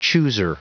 Prononciation du mot chooser en anglais (fichier audio)
Prononciation du mot : chooser